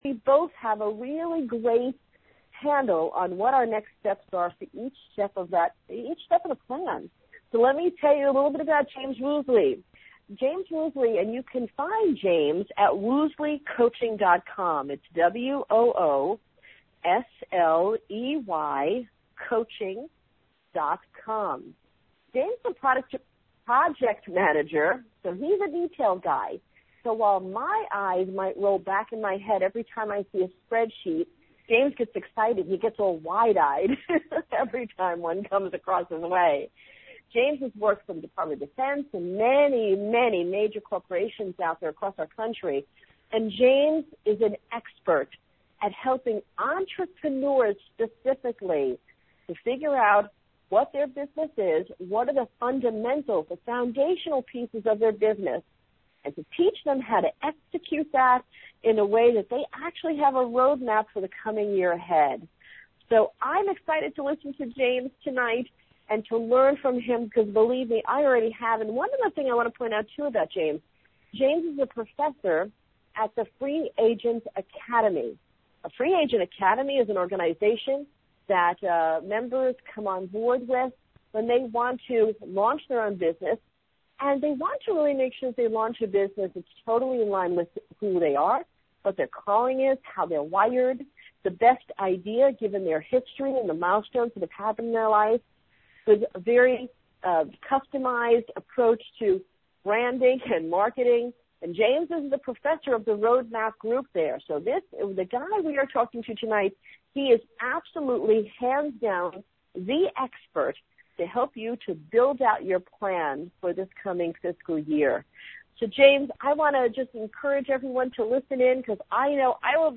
2012 Roadmap Teleseminar | Woosley Coaching